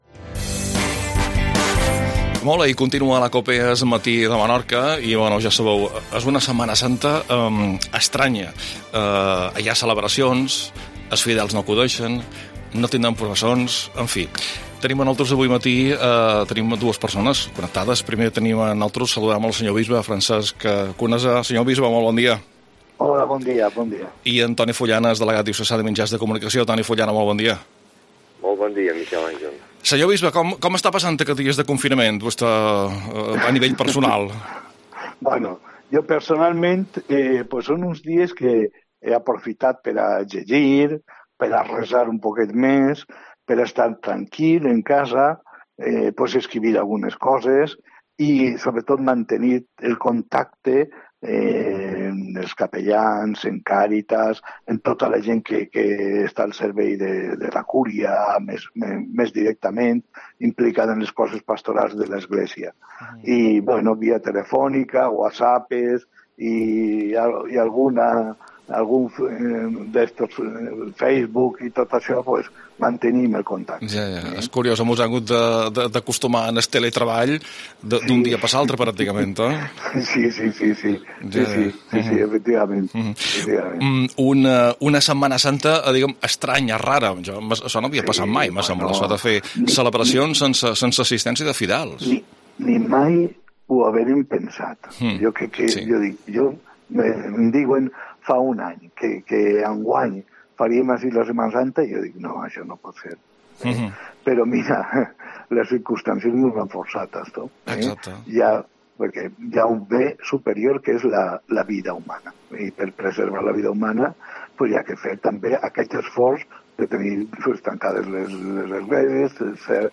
AUDIO: Entrevista al Bisbe de Menorca mons. Francesc Conesa